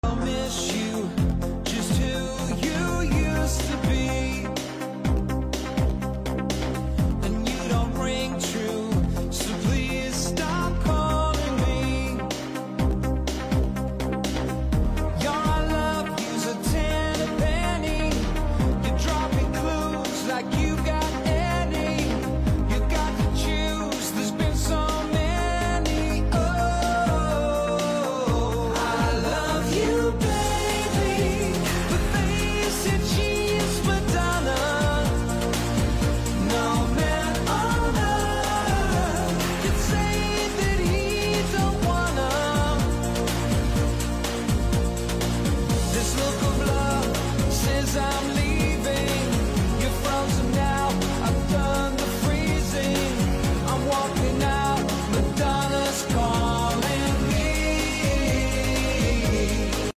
with more emphasis on dance, disco and electronic elements.